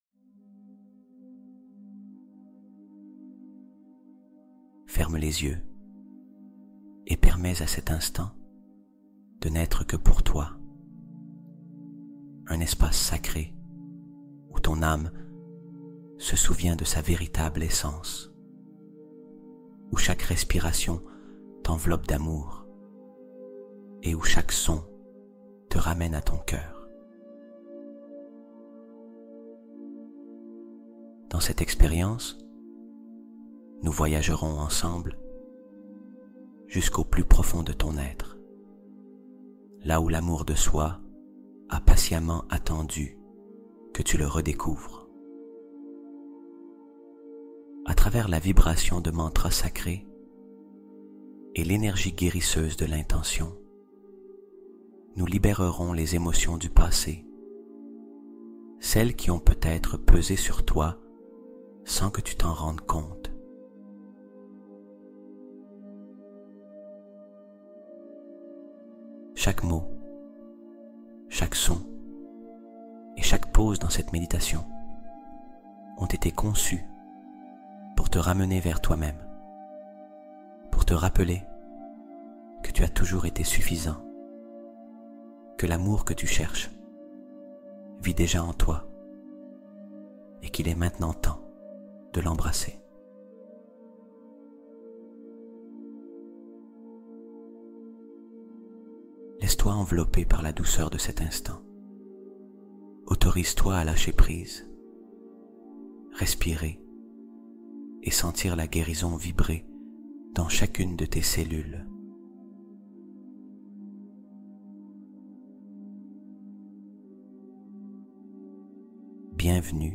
Esprit prospère : méditation subliminale nocturne structurée